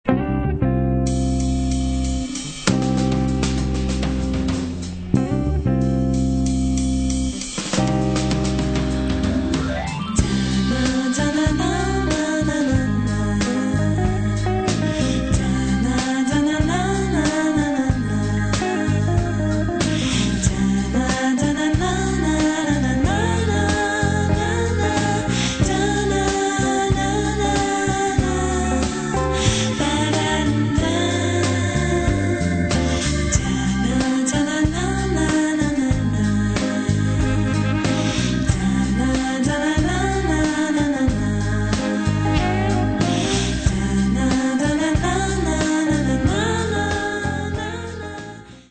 Lounge-Jazz-Combo